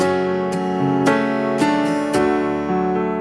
Sessionmusiker spielt komische Töne, die nicht zur Tonart gehören